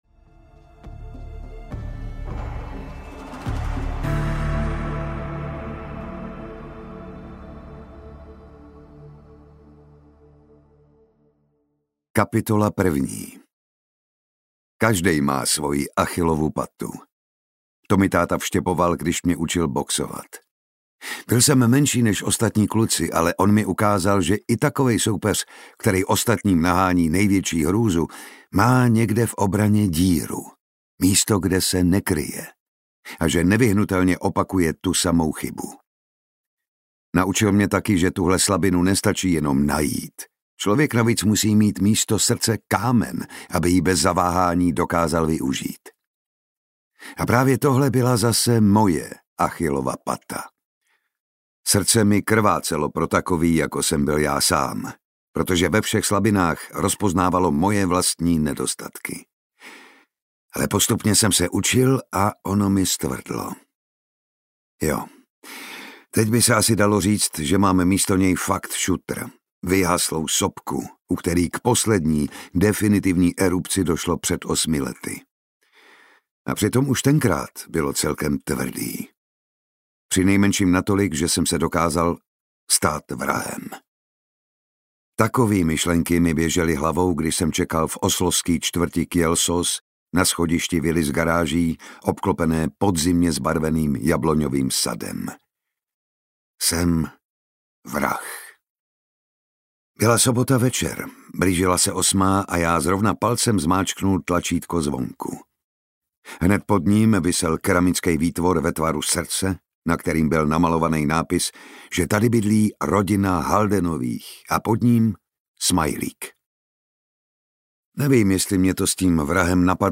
Král audiokniha
Ukázka z knihy
• InterpretJan Šťastný